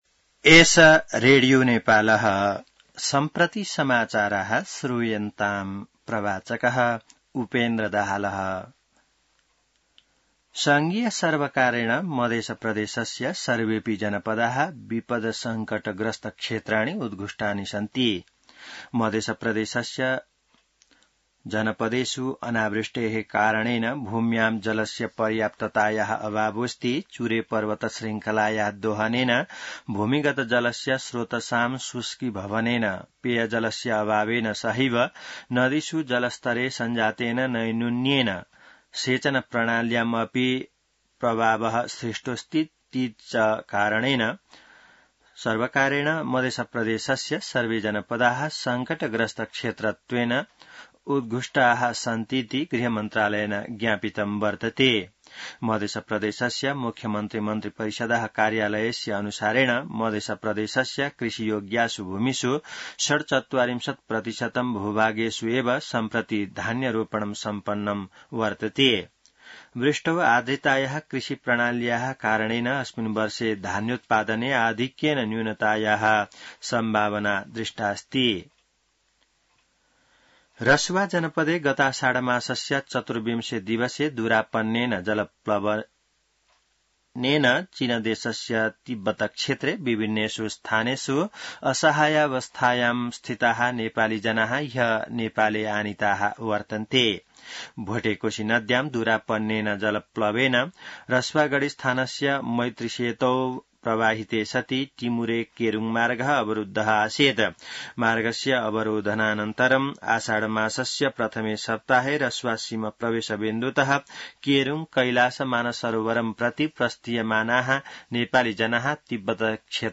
संस्कृत समाचार : ८ साउन , २०८२